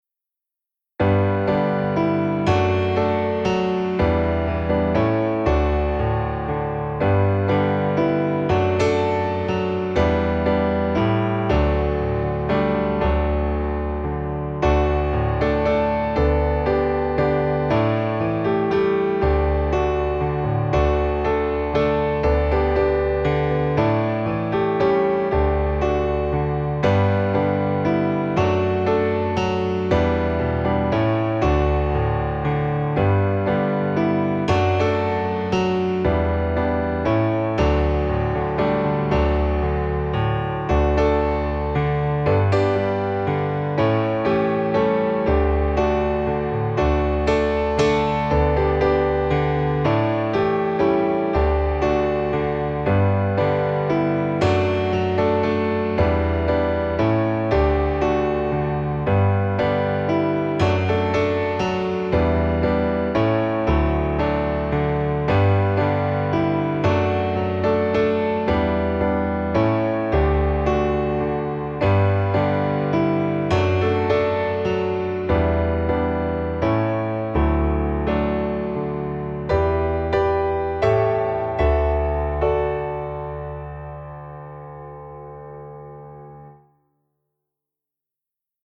pieseň zo školenia (2018 Račkova dolina) – noty s akordami, prezentácia a playback